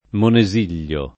Monesiglio [ mone @& l’l’o ]